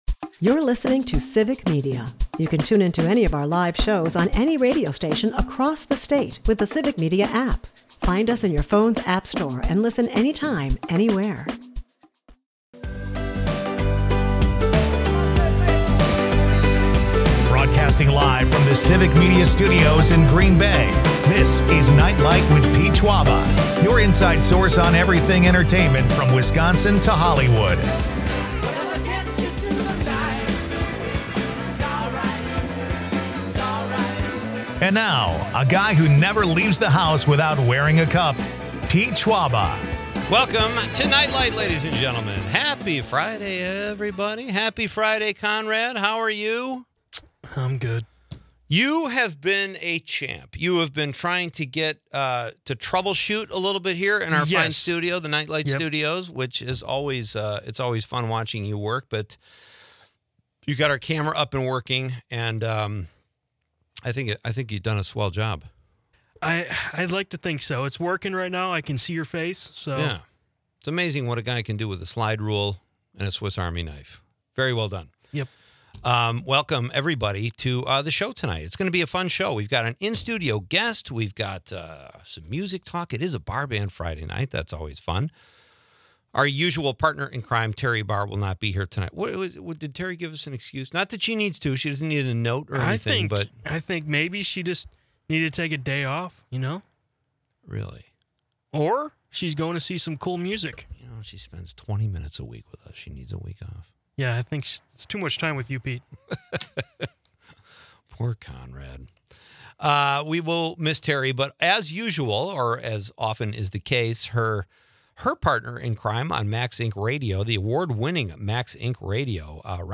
The show dives into the allure of after-bar food, with listeners suggesting everything from tacos to grilled cheese as the ultimate late-night snack.